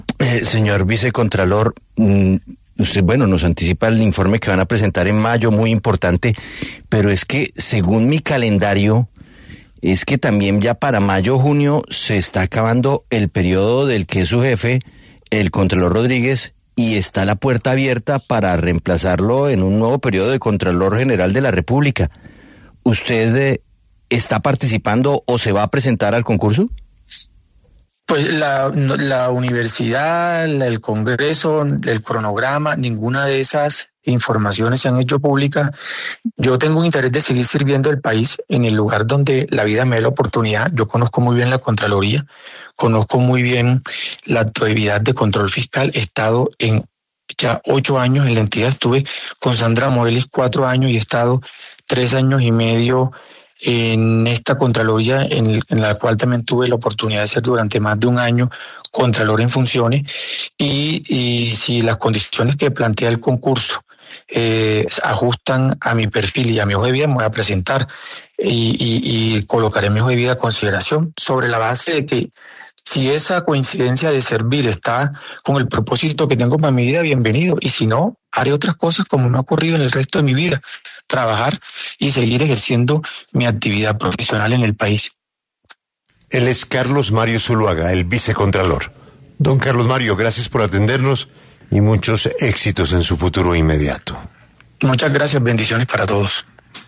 En entrevista con 6AM W, con Julio Sánchez Cristo, el vicecontralor general de la República, Carlos Mario Zuluaga, reveló que se postulará para ser nuevo contralor general en reemplazo de Carlos Hernán Rodríguez.